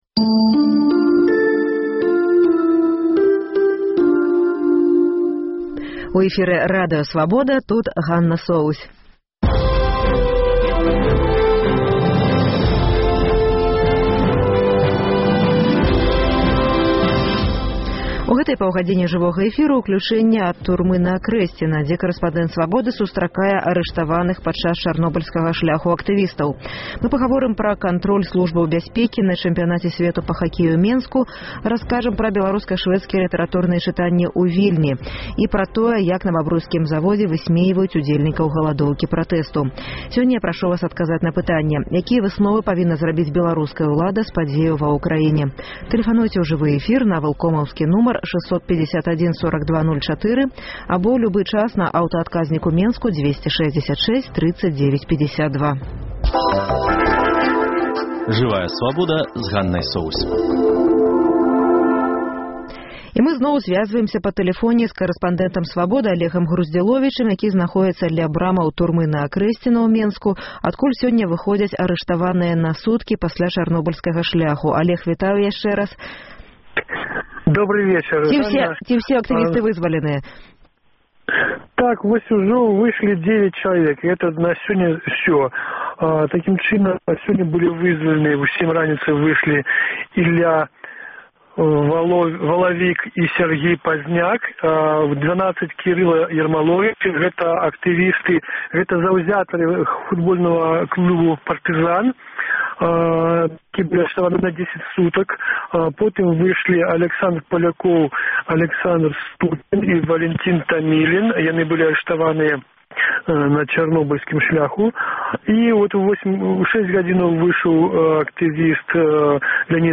Сёньня ў праграме: З Акрэсьціна вызваляюць арыштаваных падчас Чарнобыльскага шляху. Жывое ўключэньне Ці гатовы Эўразьвяз увесьці эканамічныя санкцыі супраць Расеі?